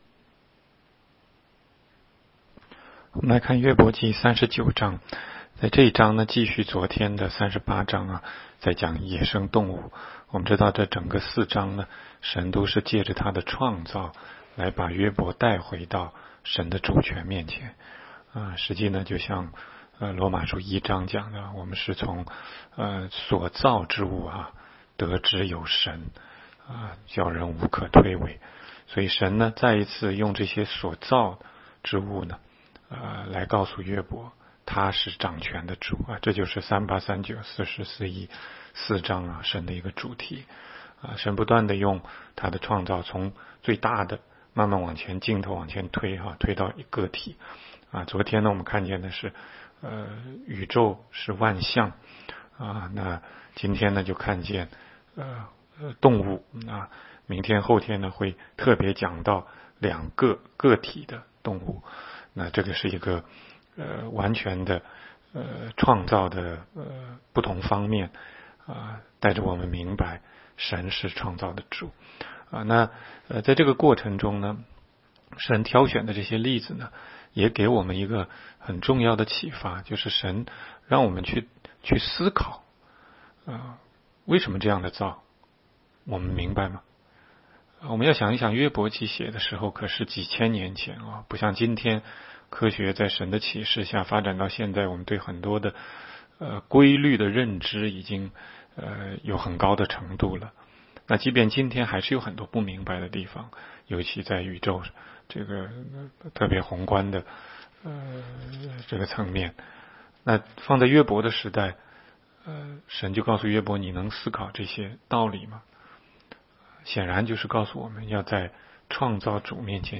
16街讲道录音 - 每日读经-《约伯记》39章